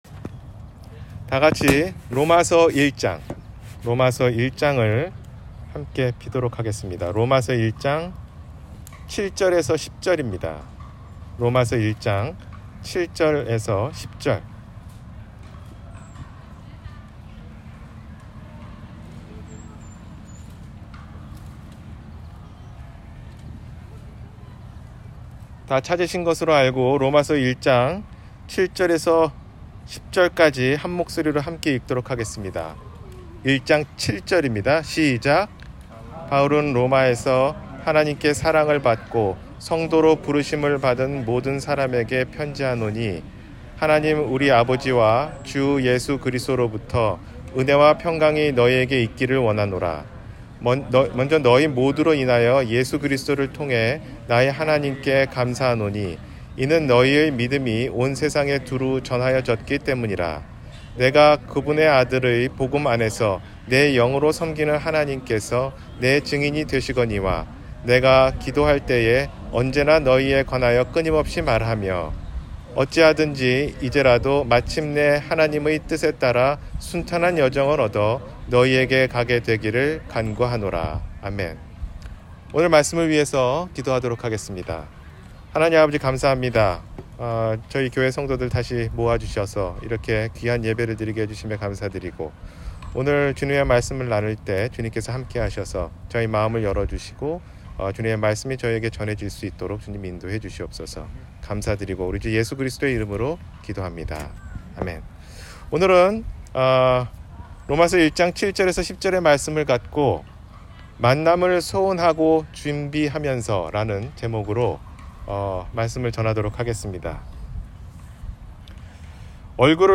만남을 소원하고 준비하면서 – 주일설교